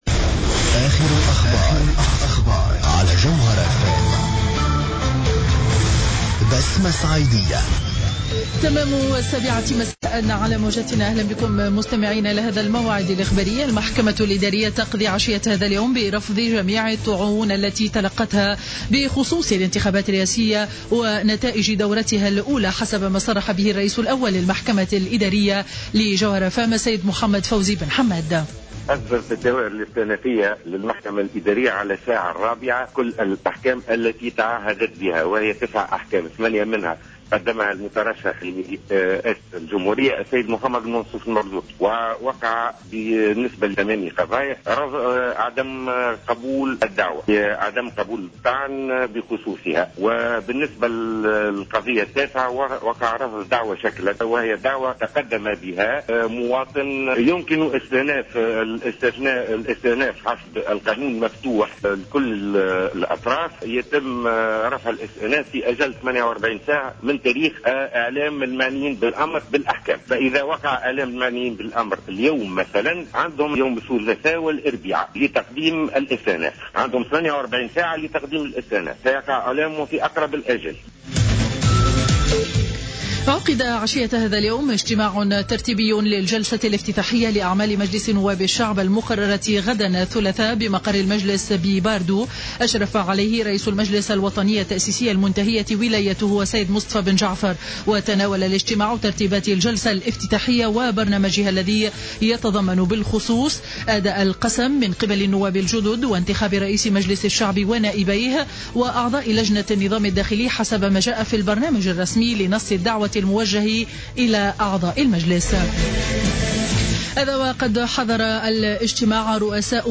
نشرة أخبار السابعة مساء ليوم 01-12-14